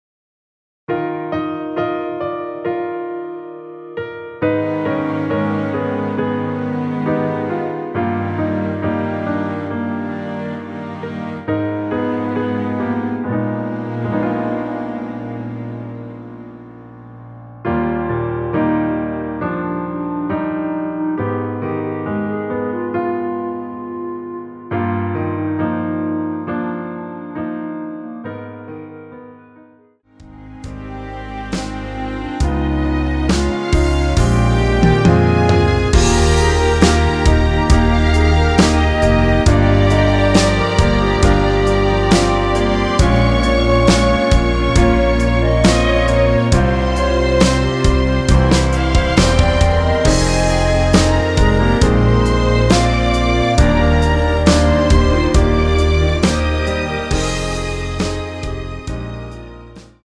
(-2)내린 멜로디 포함된 MR 입니다.(미리듣기 참조)
Eb
앞부분30초, 뒷부분30초씩 편집해서 올려 드리고 있습니다.
중간에 음이 끈어지고 다시 나오는 이유는